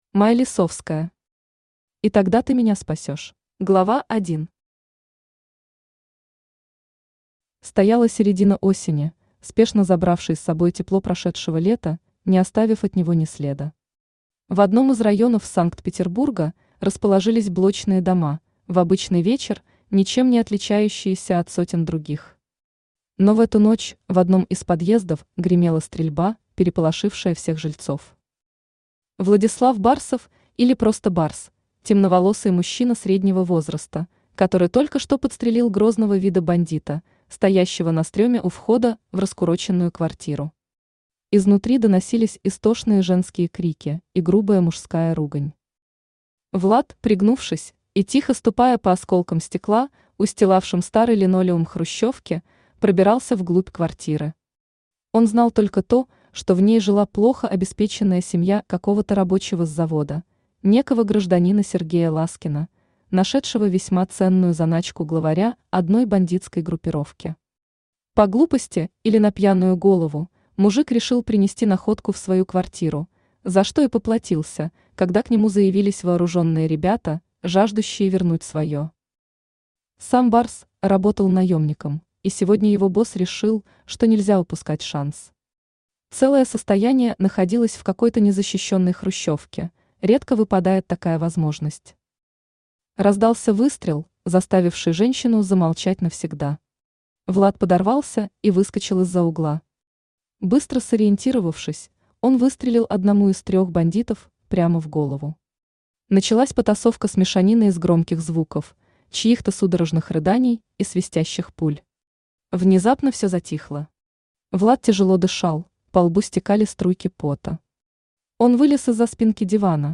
Аудиокнига И тогда ты меня спасёшь | Библиотека аудиокниг
Aудиокнига И тогда ты меня спасёшь Автор Май Лисовская Читает аудиокнигу Авточтец ЛитРес.